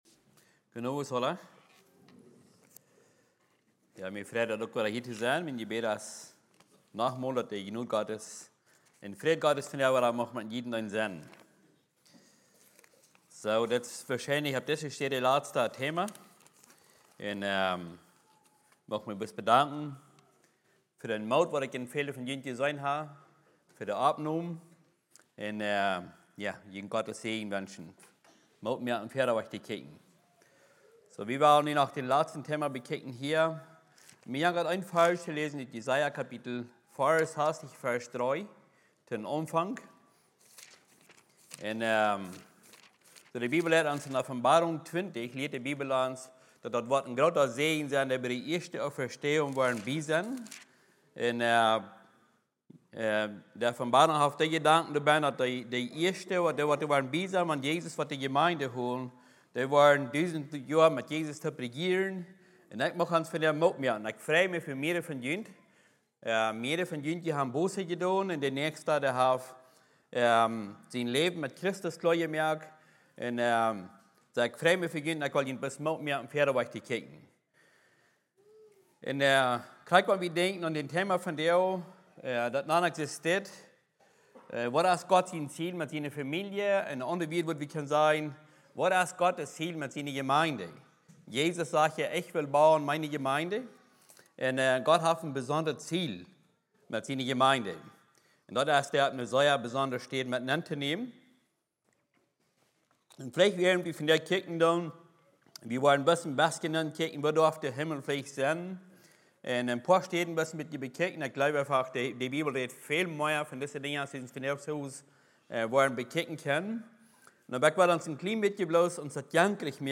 Was is Gottes Ziel mit Seiner Familie? — a sermon